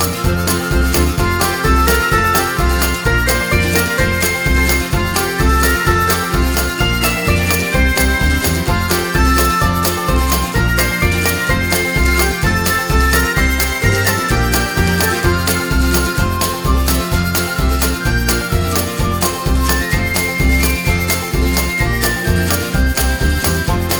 no Backing Vocals Irish 2:54 Buy £1.50